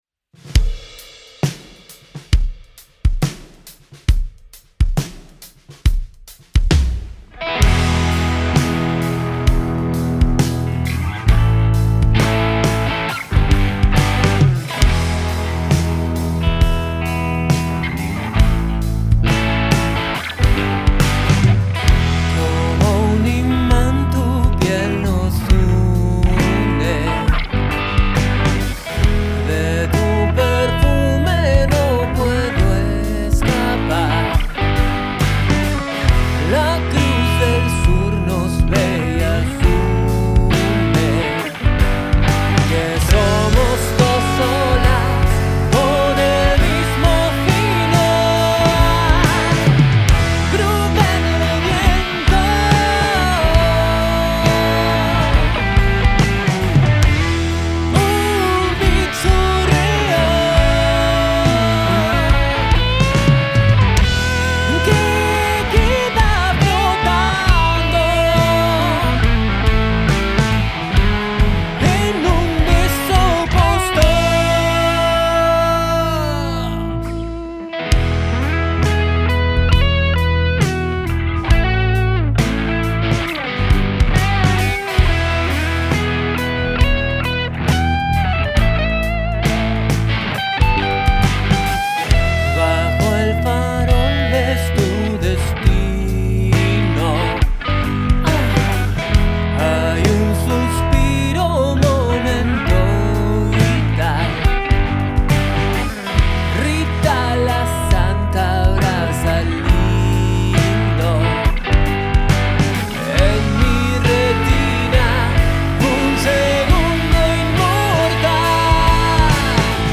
Grabada en vivo el 1 de junio de 2025
en Estudio del Monte